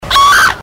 Play Someone's Scream - SoundBoardGuy
Play, download and share Someone's scream original sound button!!!!
scream_fd7H8lM.mp3